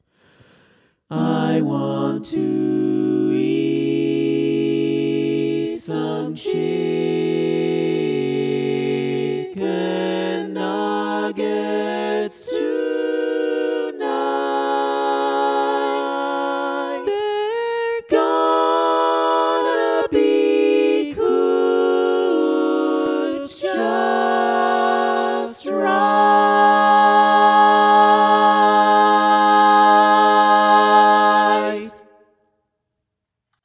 Key written in: G# Minor
How many parts: 4
Type: Barbershop
All Parts mix: